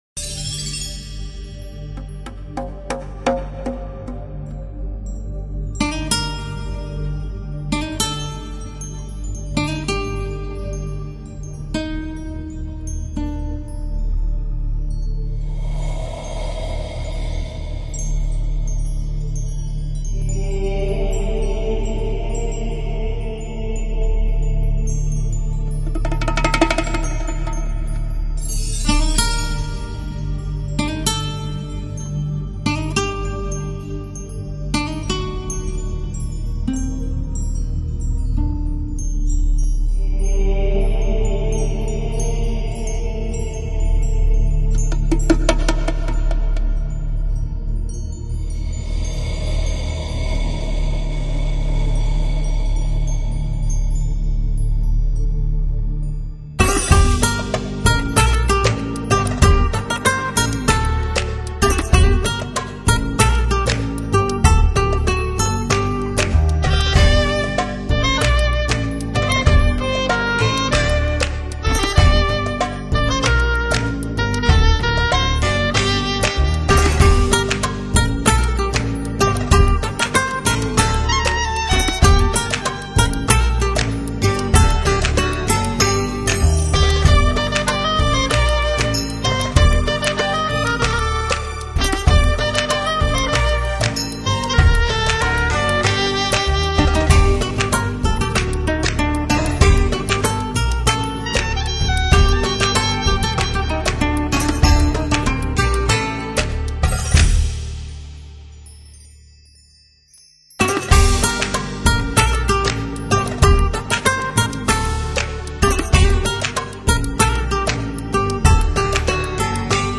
来自希腊风情音乐，聚集索帕斯之舞、阿色斯城之迷、爱神厄洛斯的 漫游、古老的诗歌、米科诺的风、悲哀、欲望……